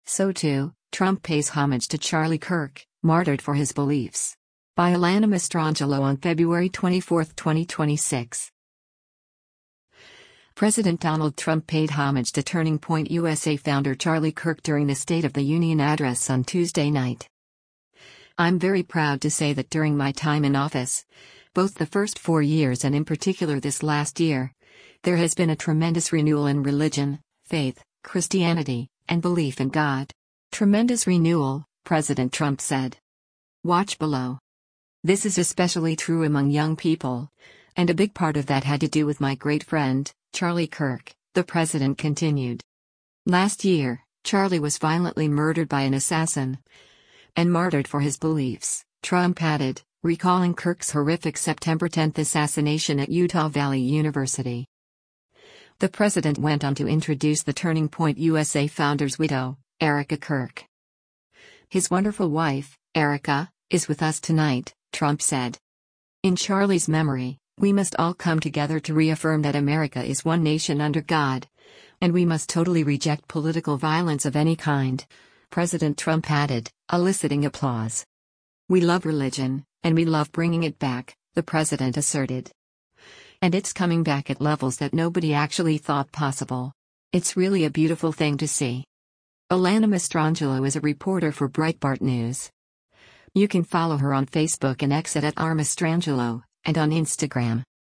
President Donald Trump paid homage to Turning Point USA founder Charlie Kirk during the State of the Union address on Tuesday night.
“In Charlie’s memory, we must all come together to reaffirm that America is one nation under God, and we must totally reject political violence of any kind,” President Trump added, eliciting applause.